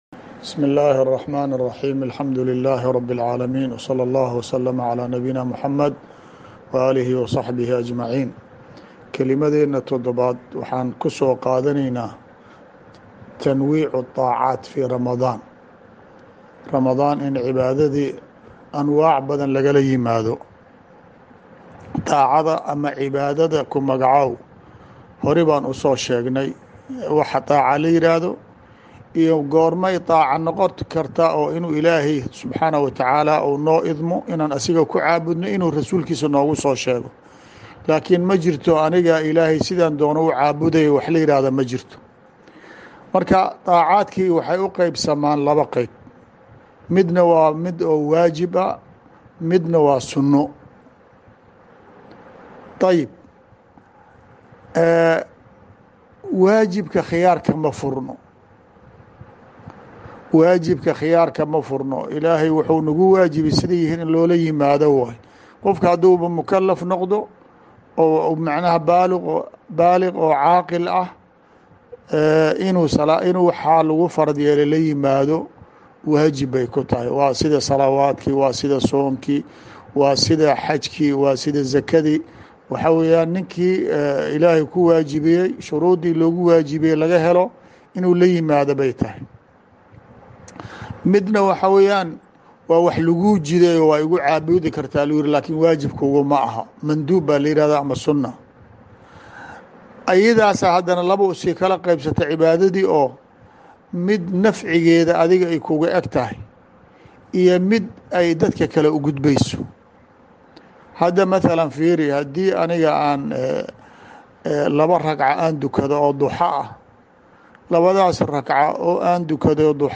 Xalqadii 7aad Duruusta Ramadaniga Ah